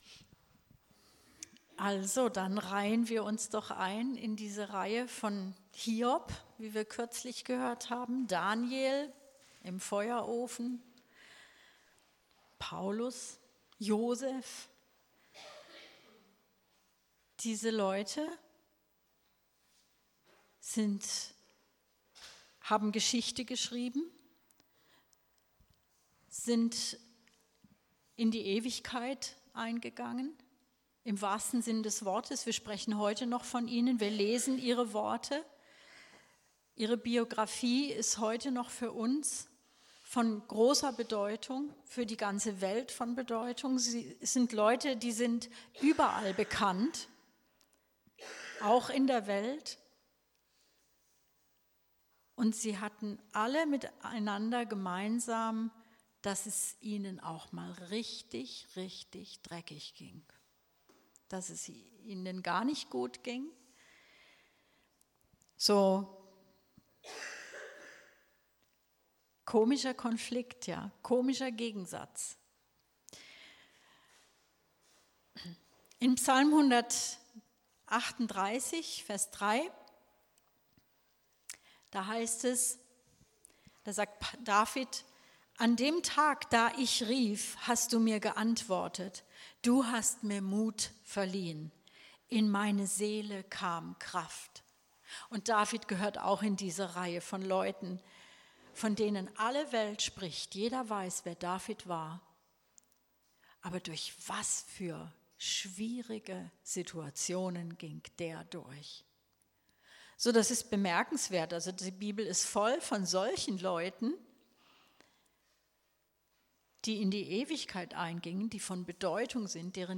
Predigt 29.07.2018: Wandel im Geist 4: Wie kann ich im Geist wandeln?